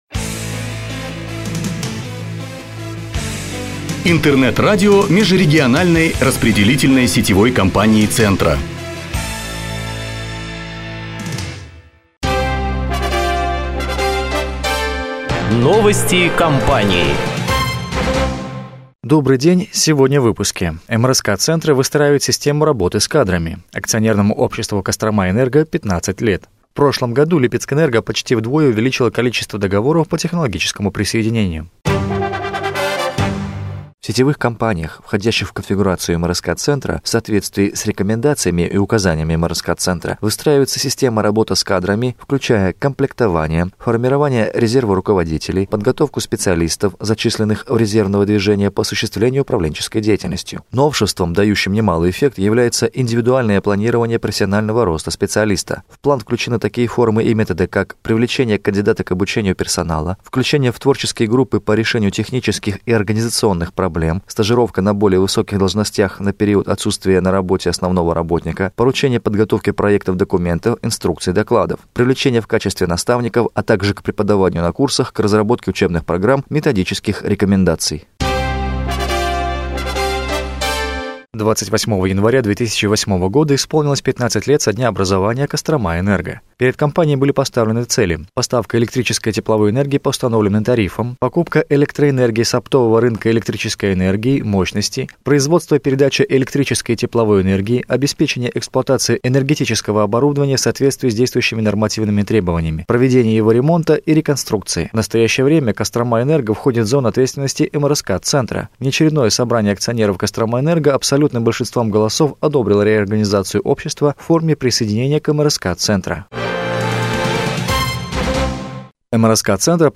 Выпуск новостей (mp3, 4 517Kb)